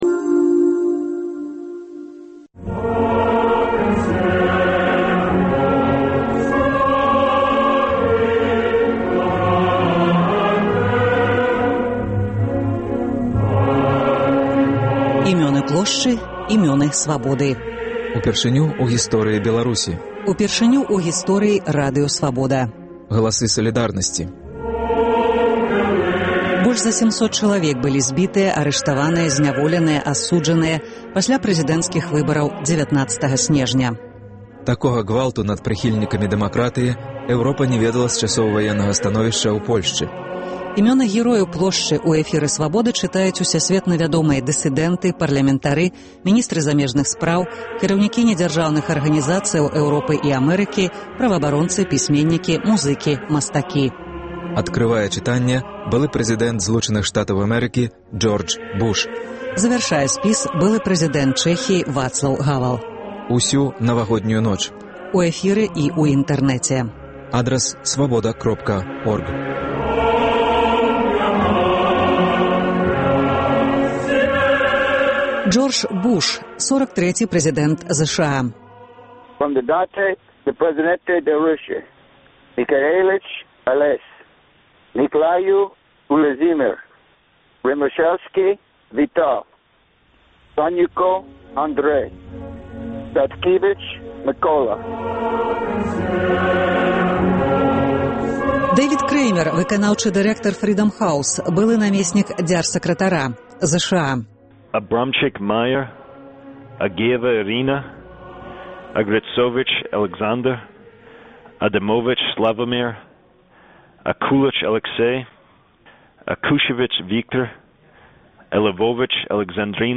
Імёны герояў Плошчы ў эфіры Свабоды чытаюць усясьветна вядомыя дысыдэнты, парлямэнтары, міністры замежных справаў, кіраўнікі недзяржаўных арганізацыяў Эўропы і Амэрыкі, праваабаронцы, пісьменьнікі, музыкі, мастакі. Адкрывае чытаньне былы прэзыдэнт Злучаных Штатаў Джордж Буш. Завяршае былы прэзыдэнт Чэхіі Вацлаў Гавэл.